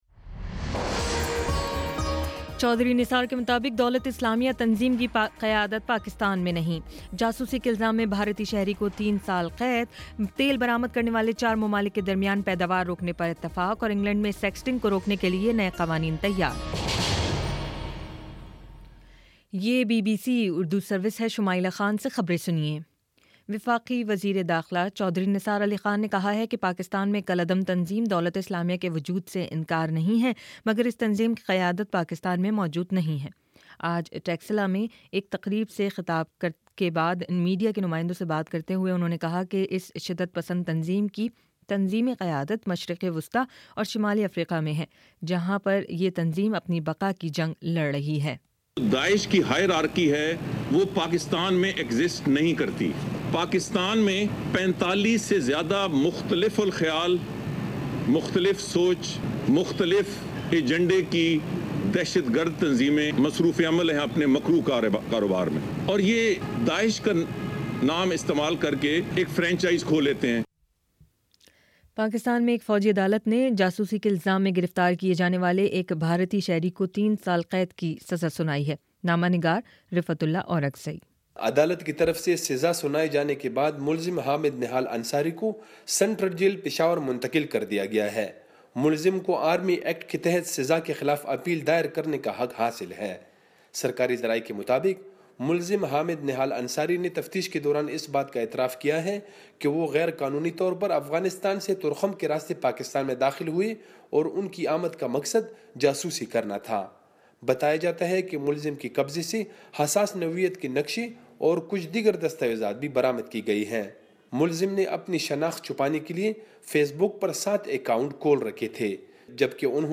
فروری 16: شام سات بجے کا نیوز بُلیٹن